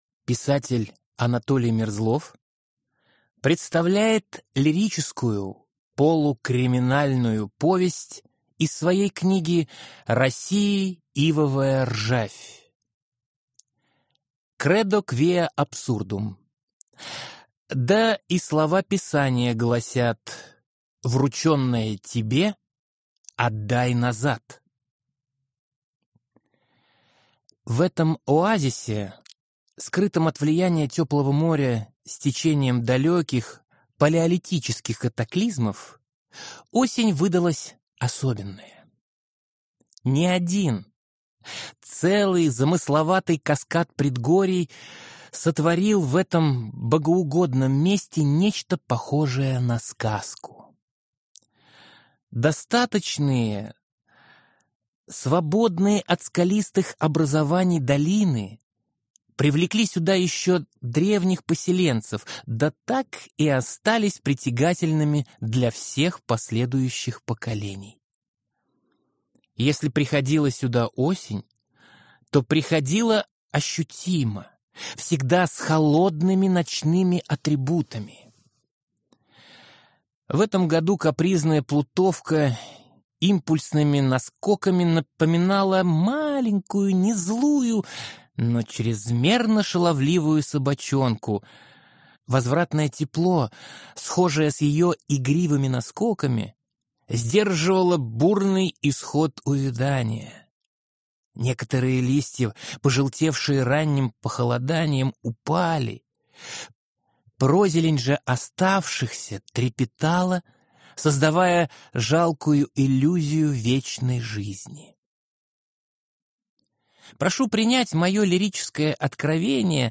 Аудиокнига Кредо квиа абсурдум | Библиотека аудиокниг